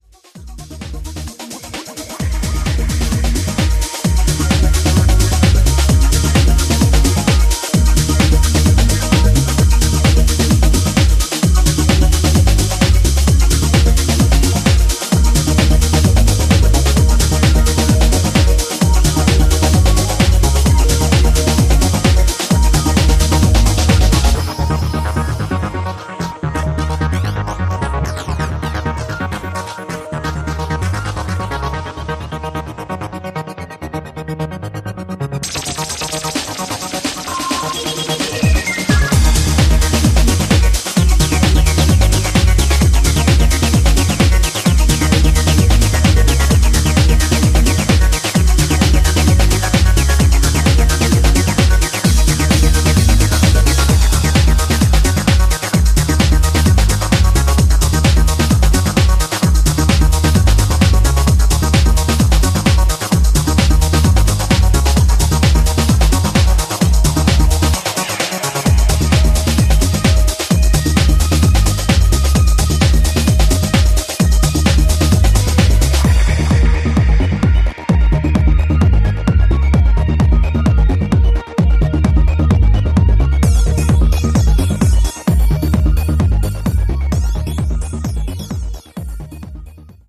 BPM130のプログレッシヴなアシッド・ブレイクビーツ・ハウス